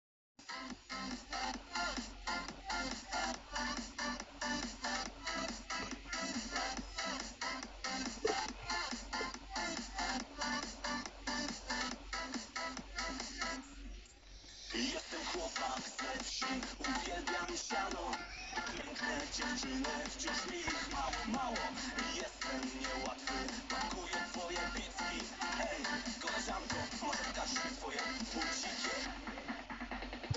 - Disco polo